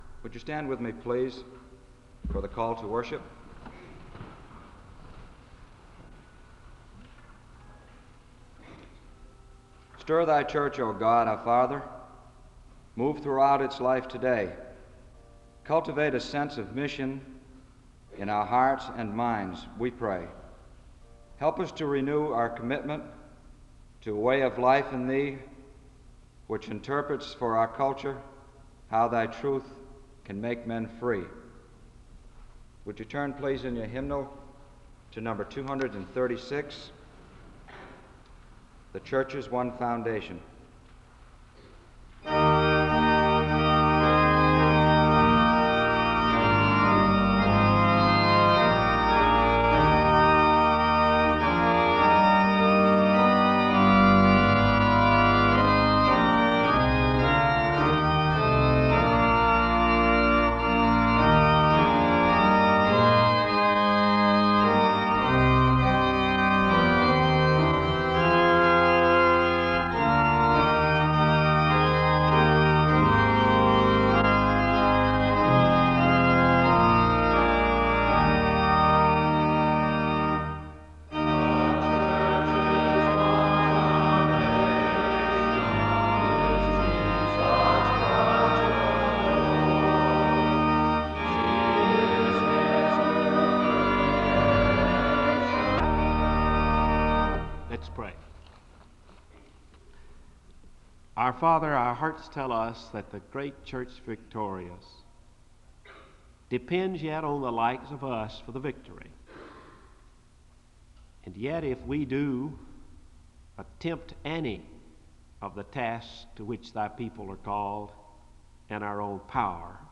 The service opens with a call to worship, and the audience sings a hymn (00:00-01:37).
A skit is performed about church growth (09:22-14:16). All persons involved in the Church Growth Conference are introduced (14:17-19:46). A group of people speak in unison (19:47-23:18). A woman reads a passage of Scripture, and it is followed by a few minutes of silence (23:19-25:35). Two people read a passage of Scripture (25:36-26:56).